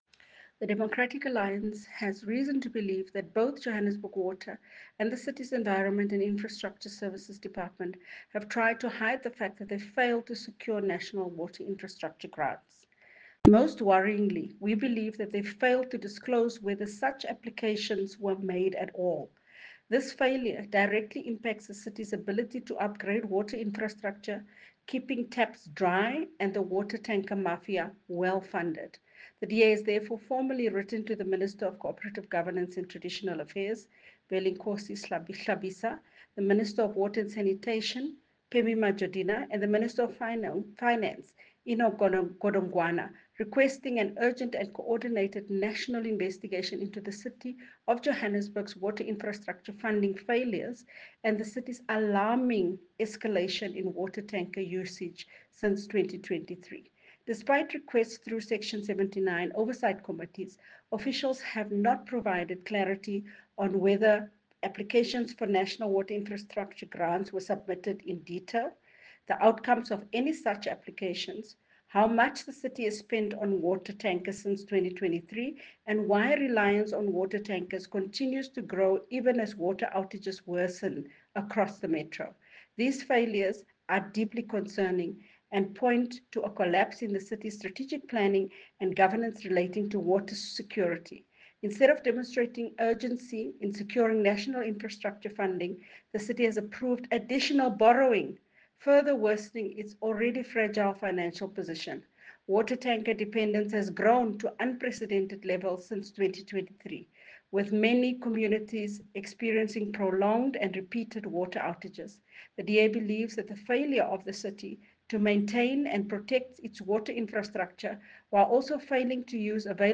English soundbite by Cllr Belinda Kayser-Echeozonjoku